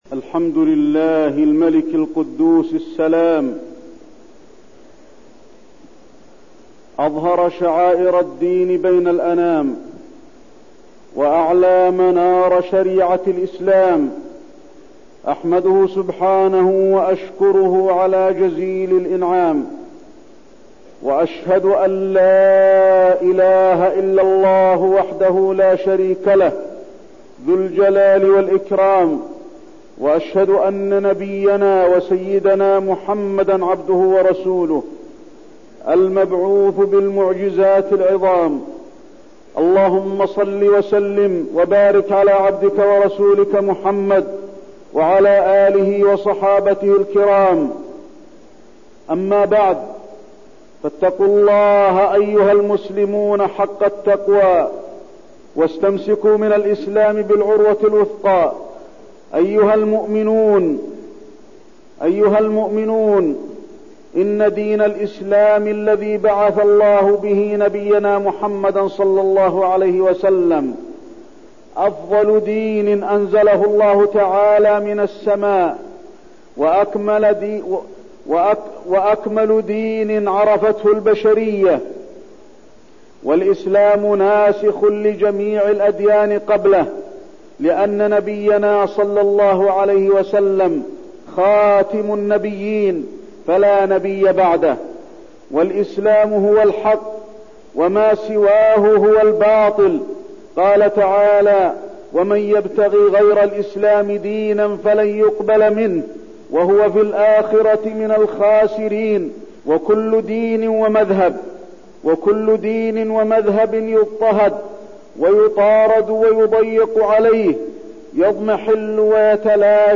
تاريخ النشر ٢ جمادى الأولى ١٤١٢ هـ المكان: المسجد النبوي الشيخ: فضيلة الشيخ د. علي بن عبدالرحمن الحذيفي فضيلة الشيخ د. علي بن عبدالرحمن الحذيفي الاذان